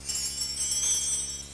1 channel
BELL14.WAV